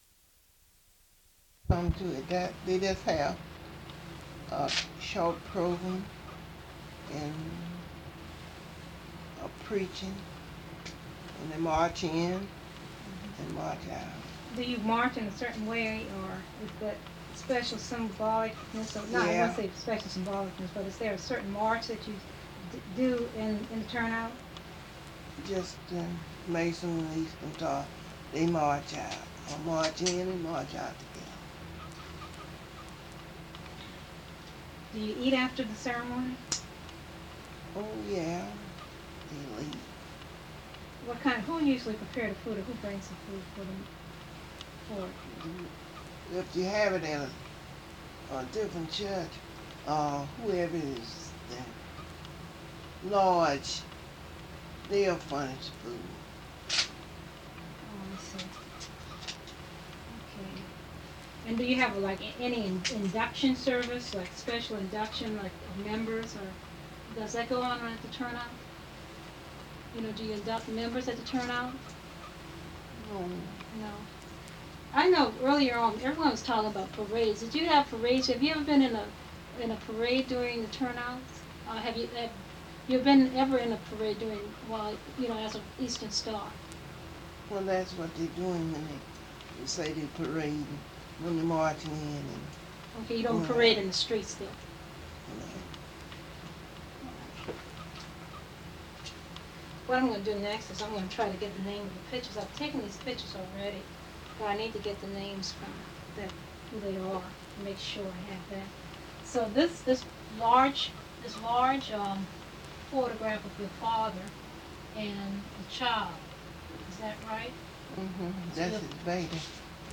Part of Interview
4:53--Audio ends, dead air for the remaining 6:50.